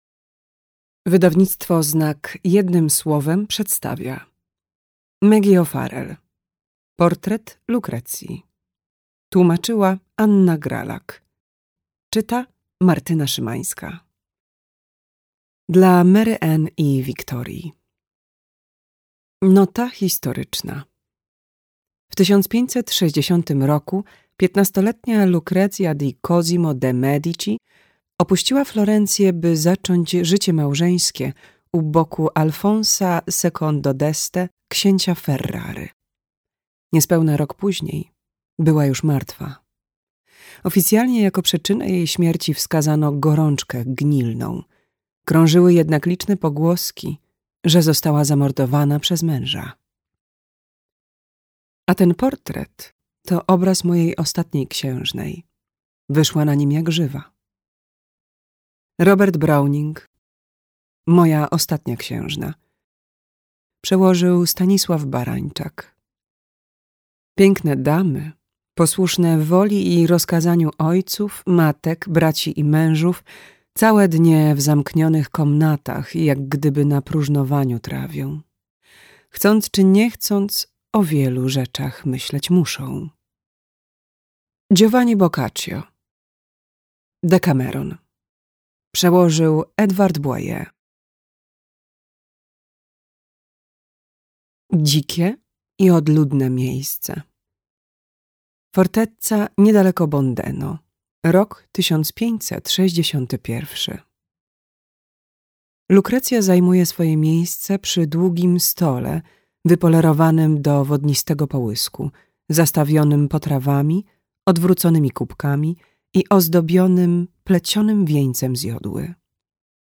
Portret Lucrezii - O’Farrell Maggie - audiobook